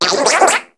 Cri de Concombaffe dans Pokémon HOME.